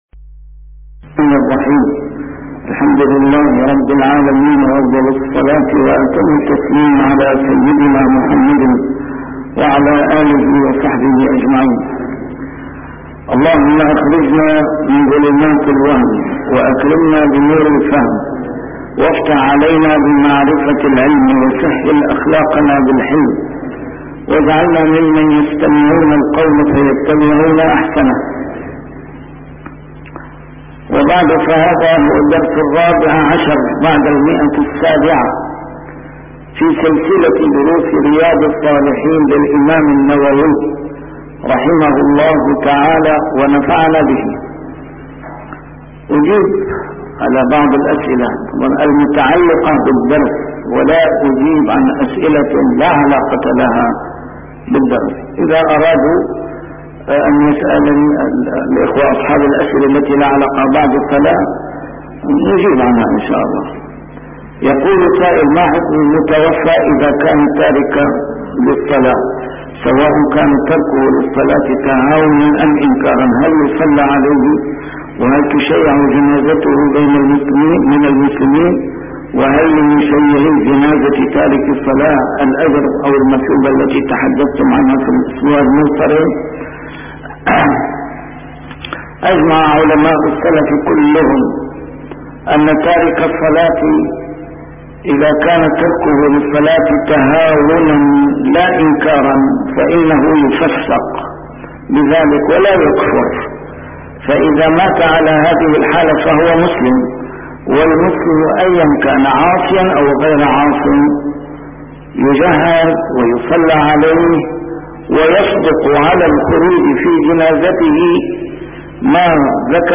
A MARTYR SCHOLAR: IMAM MUHAMMAD SAEED RAMADAN AL-BOUTI - الدروس العلمية - شرح كتاب رياض الصالحين - 714- شرح رياض الصالحين: تكثير المصلين على الجنازة